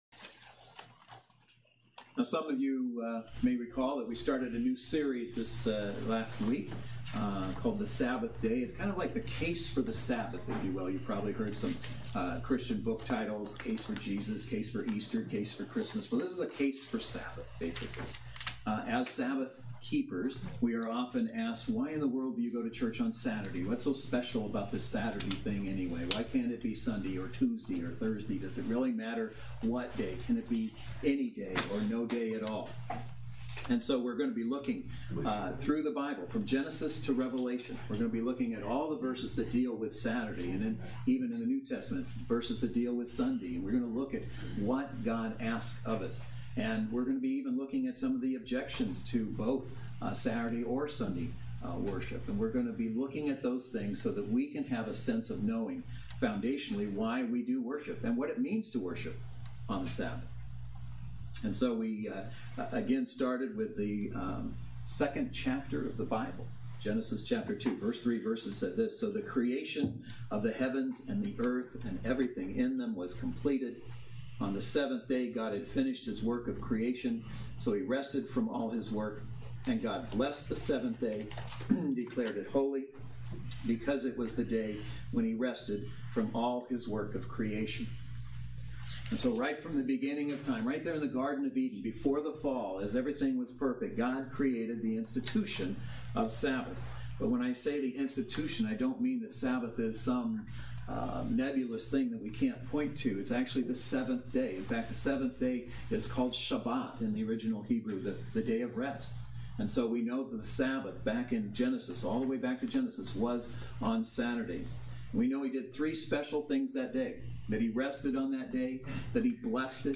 Passage: Genesis 2:1-3 Service Type: Saturday Worship Service